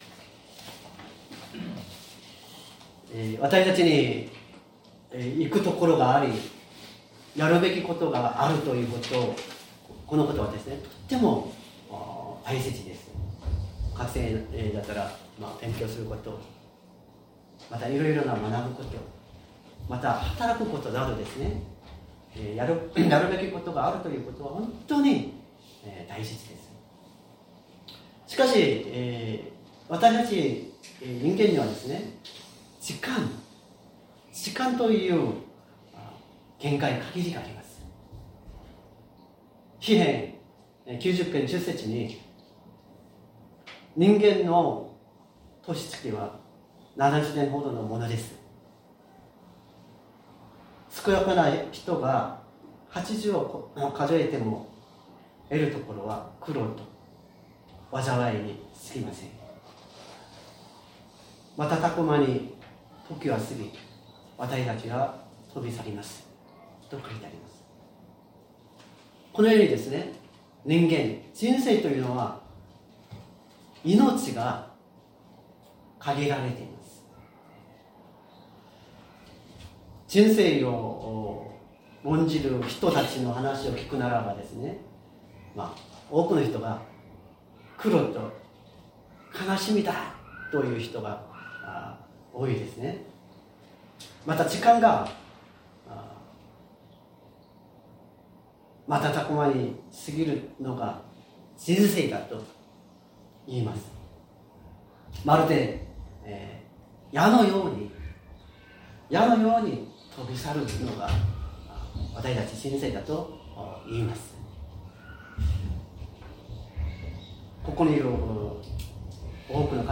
説教アーカイブ 2024年11月17日朝の礼拝「絶望と希望」
音声ファイル 礼拝説教を録音した音声ファイルを公開しています。